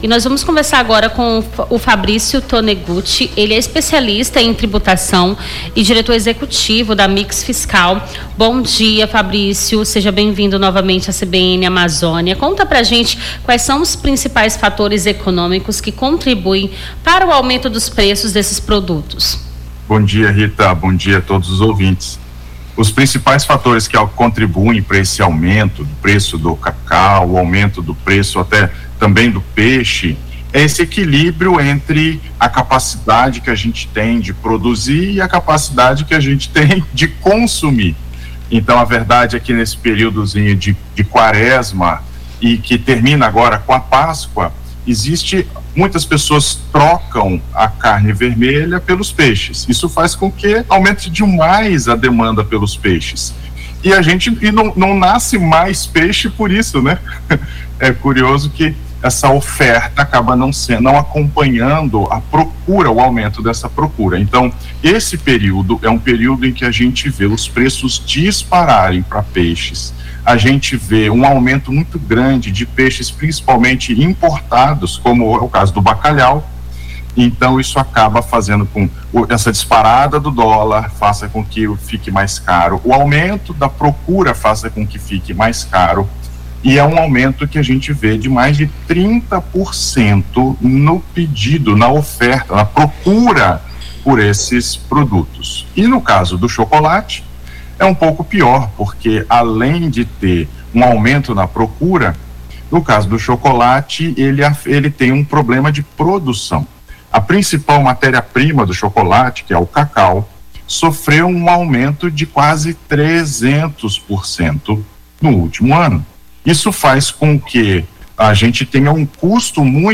AO VIVO: Confira a Programação
Nome do Artista - CENSURA - ENTREVISTA (QUARESMA PREÇO DO CACAU E PEIXE) 09-04-25.mp3